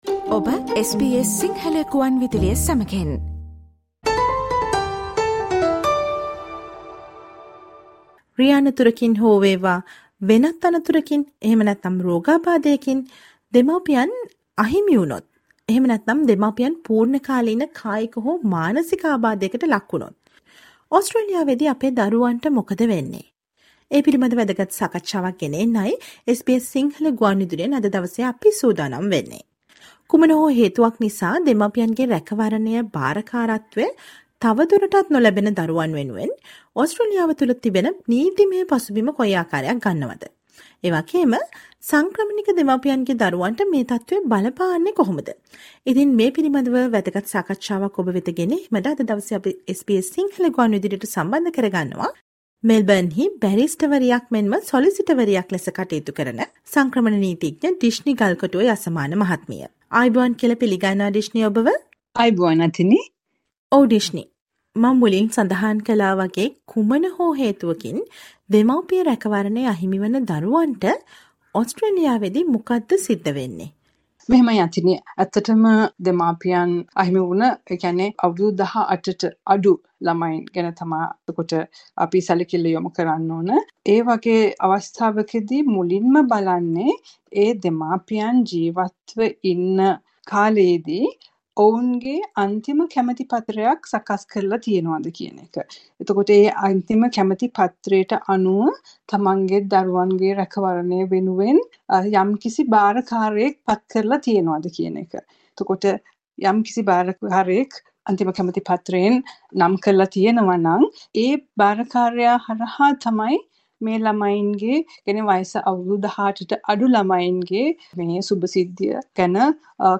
කුමන හෝ හේතුවක් නිසා දෙමාපියන්ගේ රැකවරණය , භාරකාරත්වය තවදුරටත් නොලැබෙන දරුවන් වෙනුවෙන් ඔස්ට්‍රේලියාව තුළ නීතිමය පසුබිම කොයි ආකාරයක් ගන්නවද? සංක්‍රමණික දෙමාපියන්ගේ දරුවන්ට මේ තත්වය බලපාන්නේ කොහොමද ? මේ ගැන දැනුවත් වෙන්න සවන් දෙන්න මේ ගුවන් විදුලි සාකච්ඡාවට